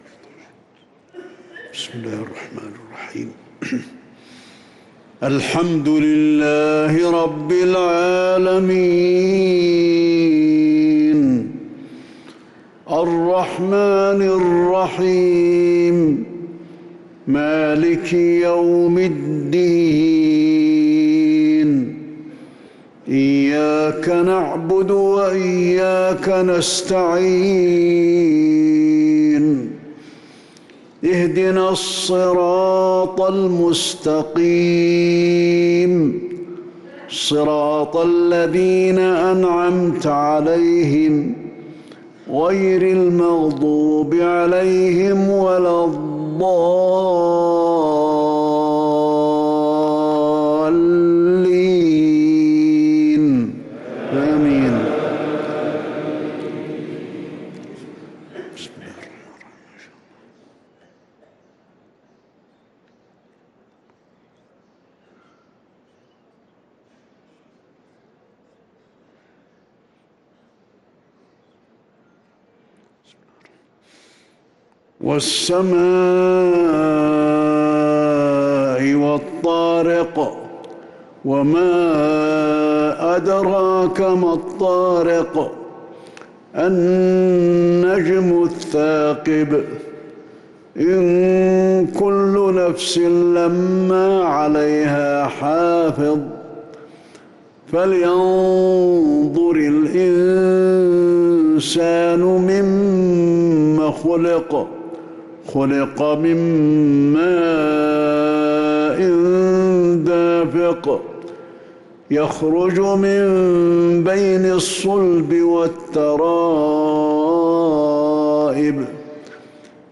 صلاة المغرب للقارئ علي الحذيفي 15 رجب 1445 هـ
تِلَاوَات الْحَرَمَيْن .